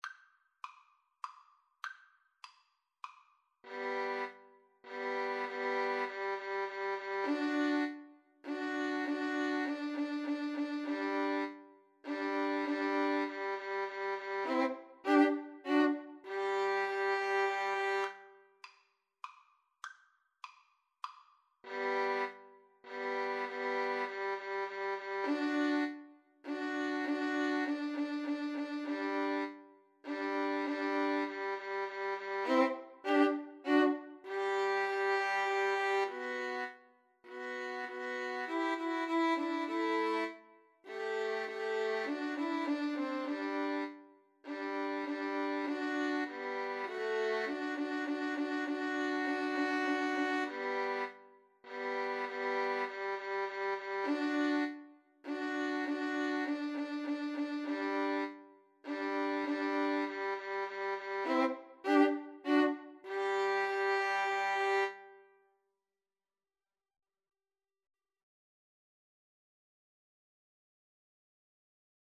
Play (or use space bar on your keyboard) Pause Music Playalong - Player 1 Accompaniment Playalong - Player 3 Accompaniment reset tempo print settings full screen
Allegro moderato (View more music marked Allegro)
G major (Sounding Pitch) (View more G major Music for Violin Trio )
3/4 (View more 3/4 Music)